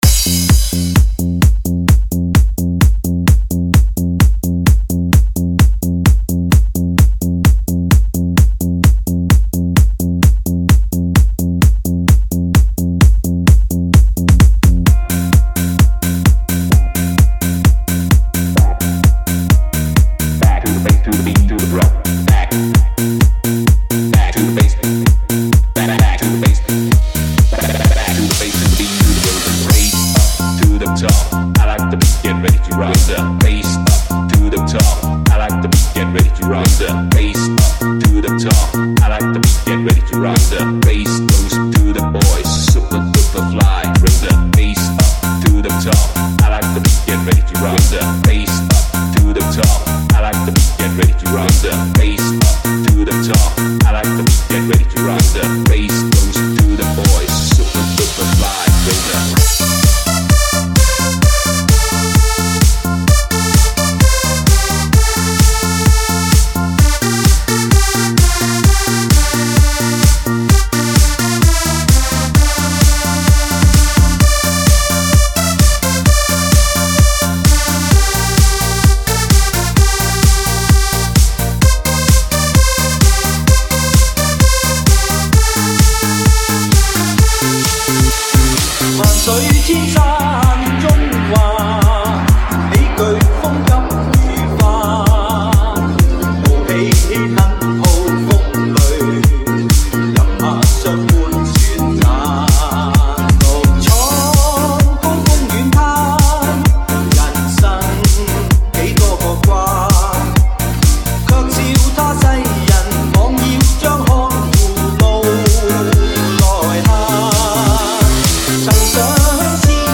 迪厅首播快嗨酒吧DJ舞曲 超级震撼音乐 即将给你带来最动人心扉的舞曲
三维高临场音效 采用德国1:1母带直刻技术
整首歌气势磅礴，表现出了英雄豪杰的气势。